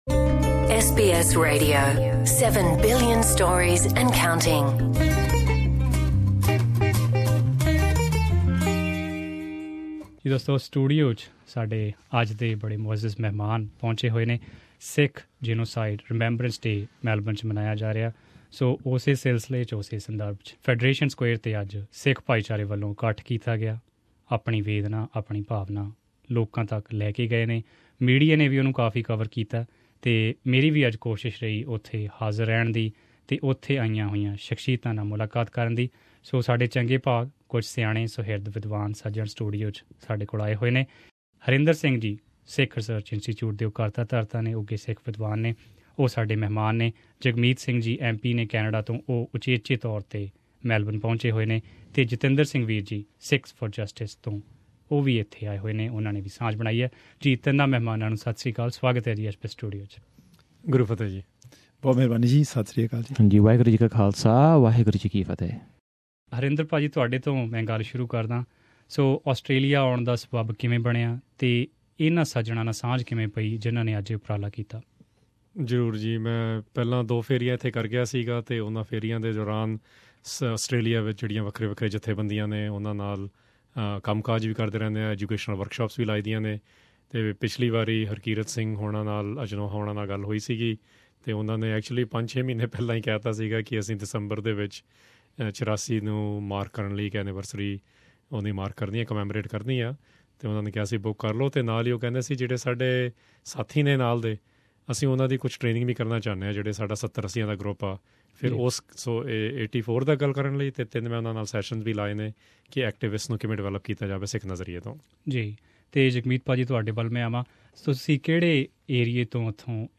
Sikhs along with other community representatives from all around Australia started peaceful march from the State Library of Victoria at 10am, reaching Fed Square at 10.30am where the community leaders made speeches and joined hands against genocides.